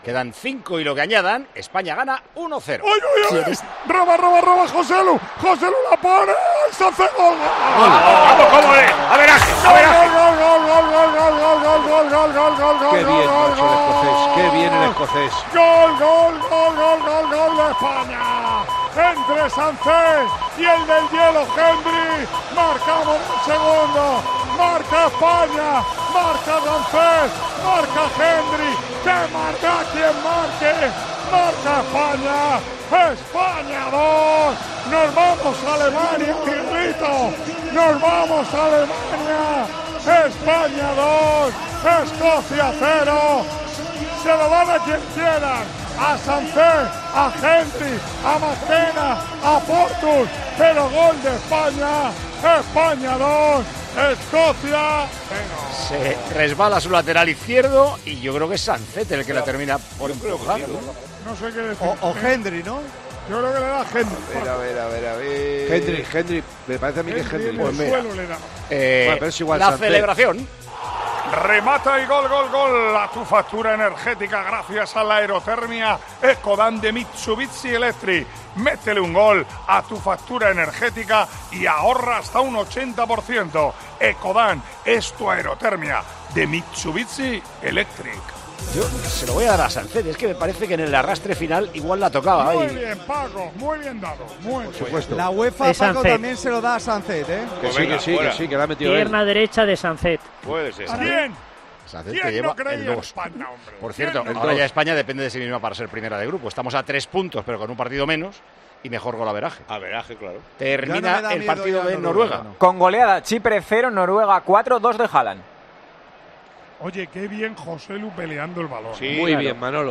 Así vivimos en Tiempo de Juego la retransmisión del España - Esocia